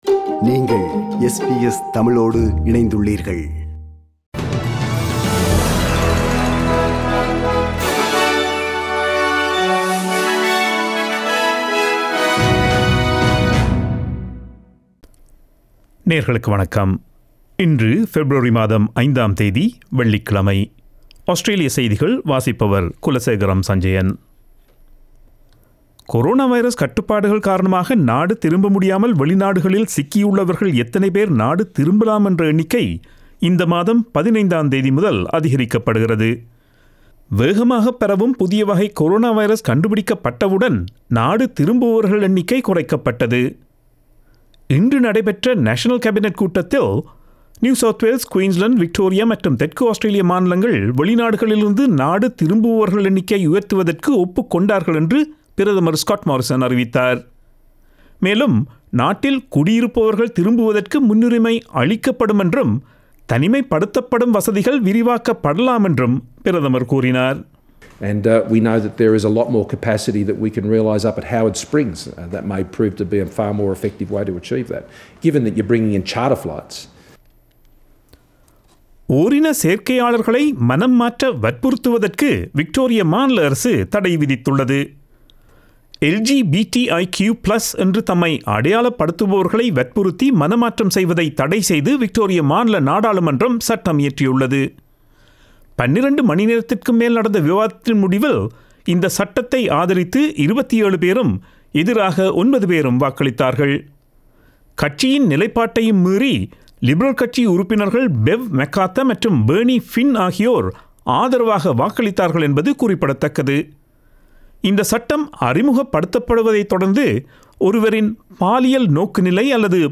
Australian news bulletin for Friday 05 February 2021.